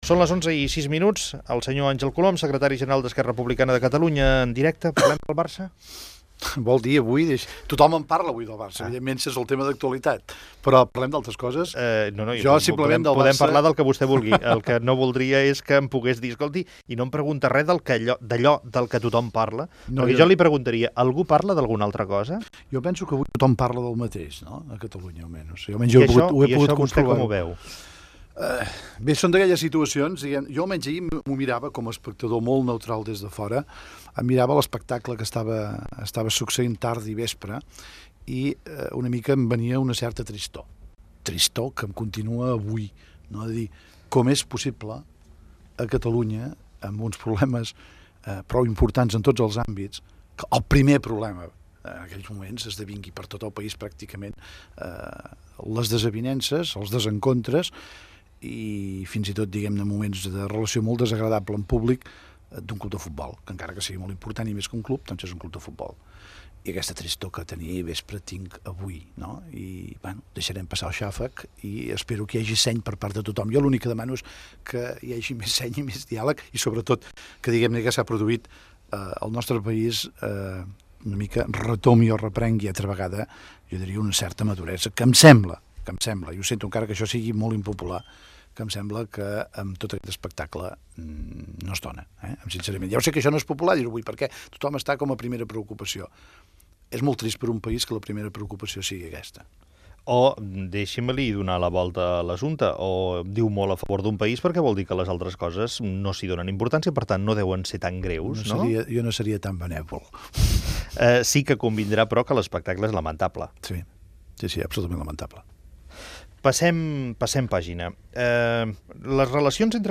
Fragment d'una entrevista al polític d'Esquerra Republicana de Catalunya Àngel Colom.
Info-entreteniment